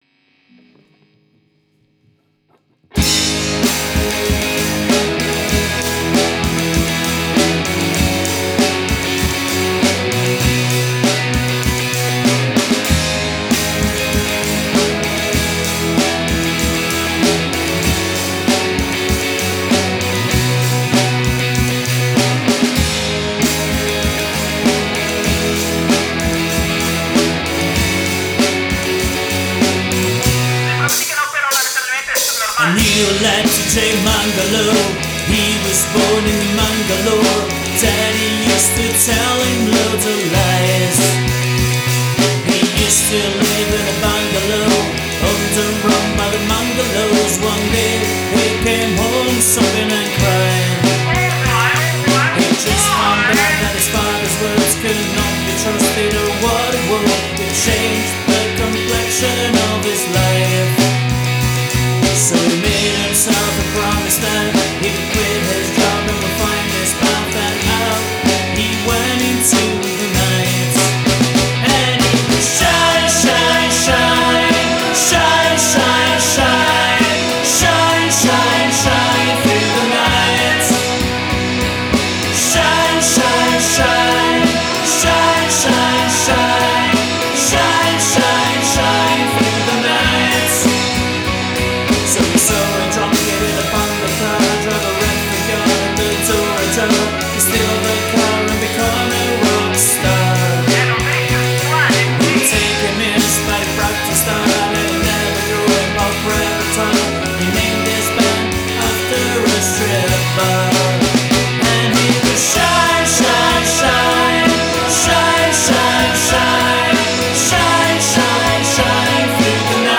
vocals, guitars, bass, drums, keyboards